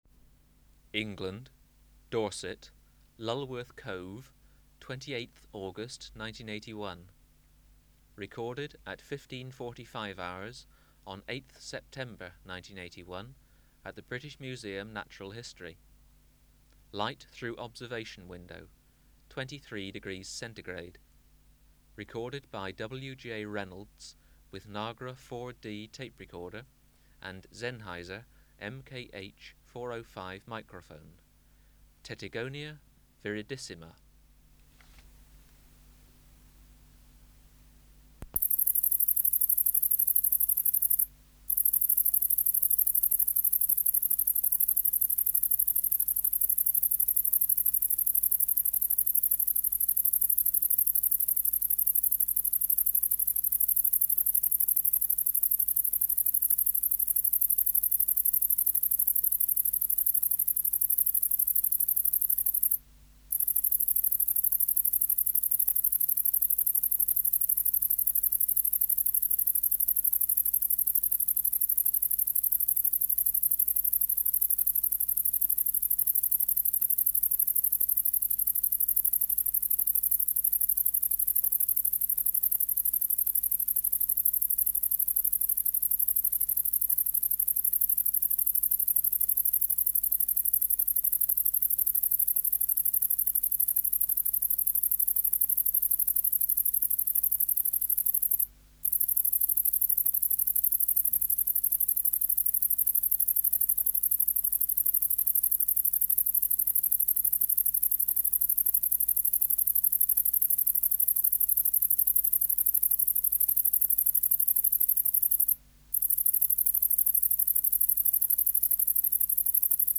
Project: Natural History Museum Sound Archive Species: Tettigonia viridissima
Recording Location: BMNH Acoustic Laboratory
Reference Signal: 1 kHz for 10 s
Substrate/Cage: Large recording cage
Microphone & Power Supply: Sennheiser MKH 405 Distance from Subject (cm): 30